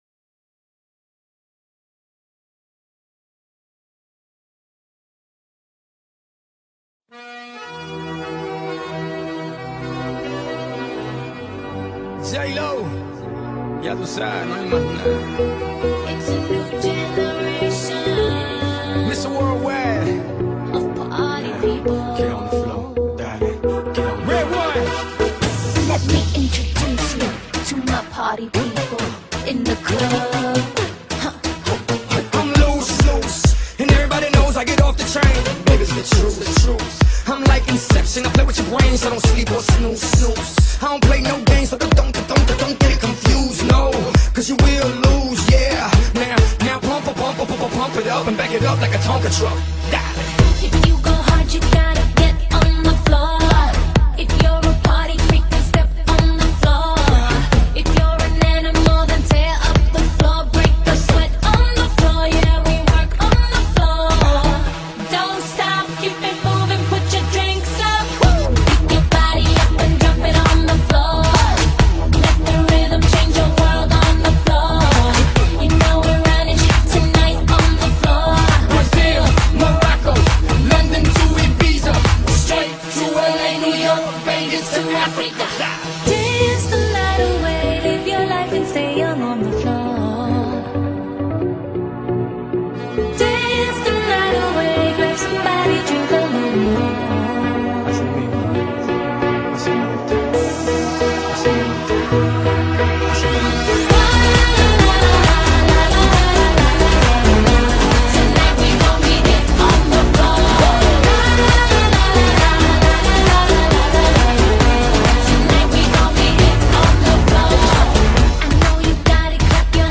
Track6_Pop.mp3